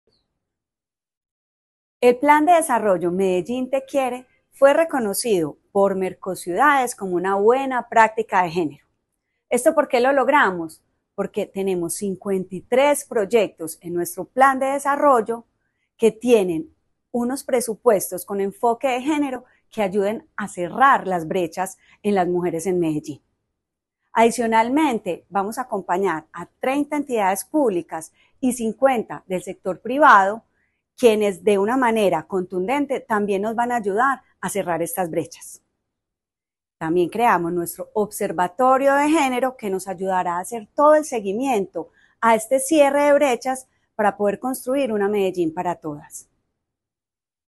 Palabras de Valeria Molina Gómez, secretaria de las Mujeres